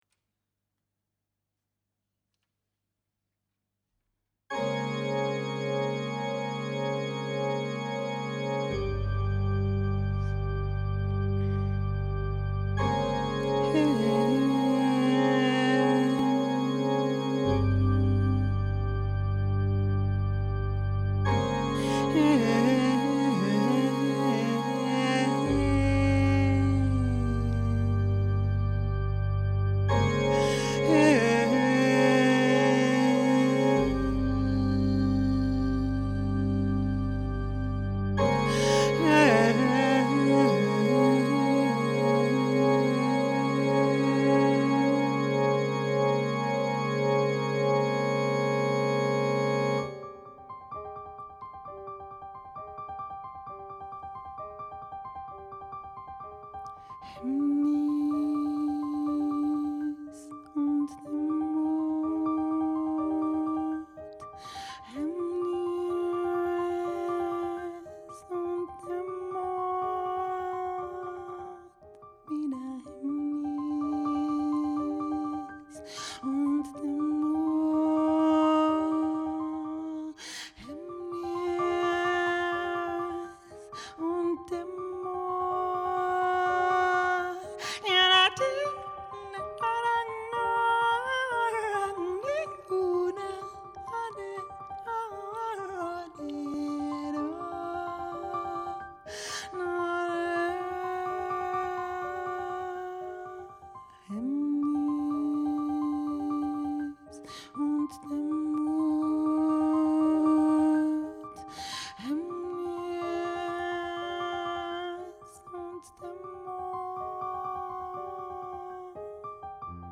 vocal, piano